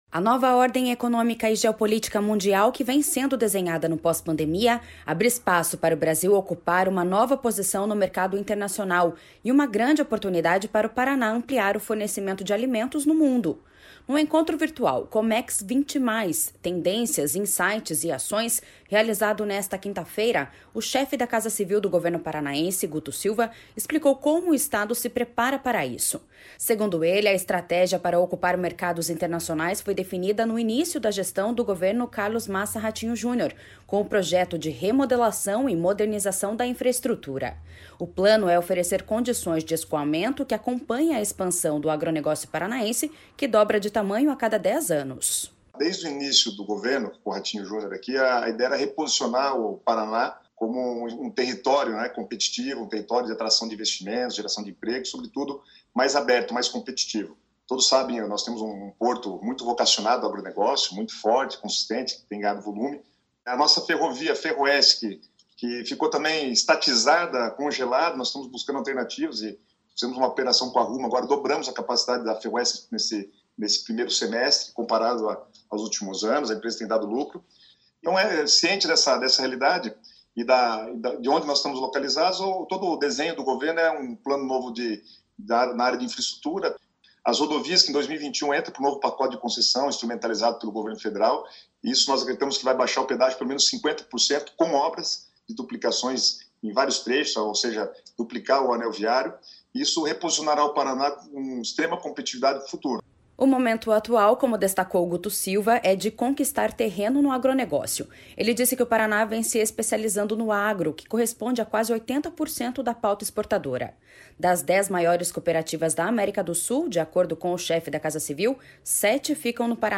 No encontro virtual Comex 20+ - Tendências, Insights e Ações, realizado nesta quinta-feira, o chefe da Casa Civil do governo paranaense, Guto Silva, explicou como o Estado se prepara para isso.
O plano é oferecer condições de escoamento que acompanhem a expansão do agronegócio paranaense, que dobra de tamanho a cada 10 anos.// SONORA GUTO SILVA.//